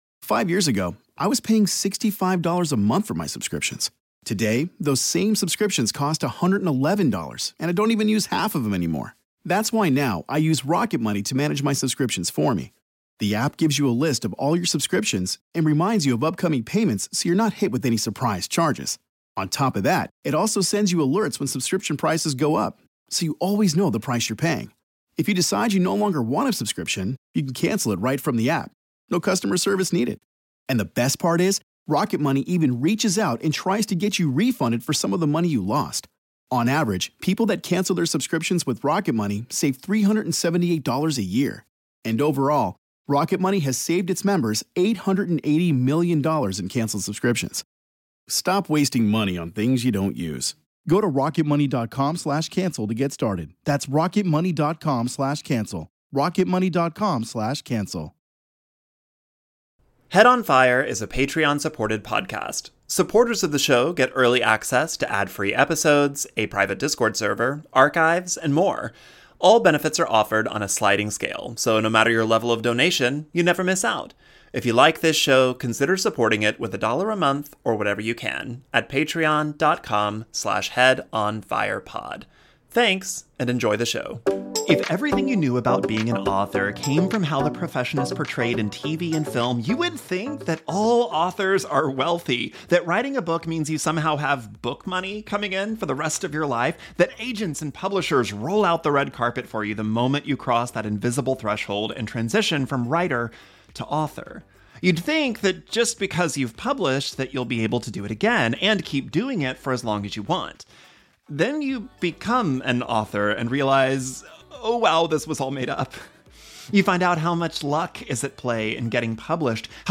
I sat down with Victoria to have a long discussion about the actual job of being an author, what readers and prospective authors alike get right and wrong about the labor of a creative career, and how to reframe rejection for yourself as just another part of the job.